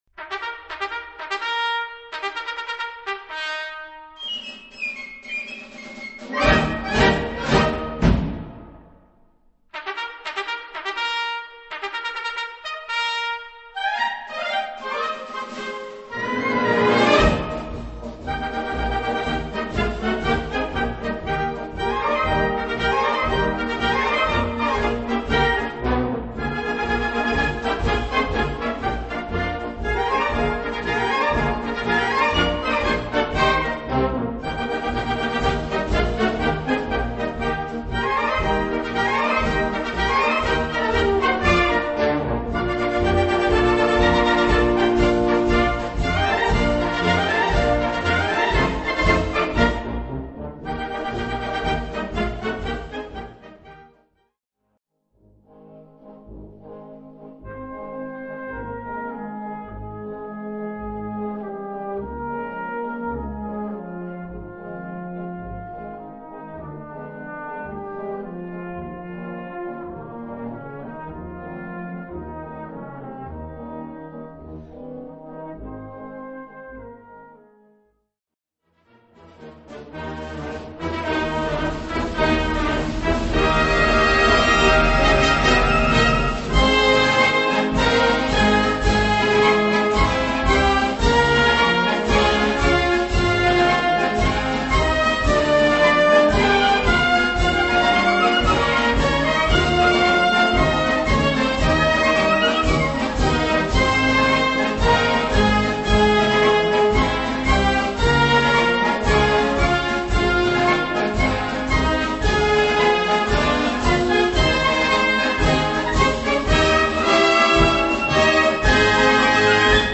Gattung: Marsch op. 214
Besetzung: Blasorchester